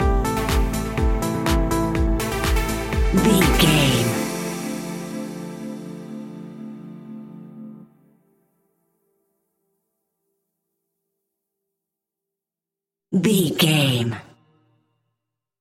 Cool Tropical Chilled House Stinger.
Aeolian/Minor
groovy
smooth
uplifting
drum machine
electro
instrumentals
synth drums
synth leads
synth bass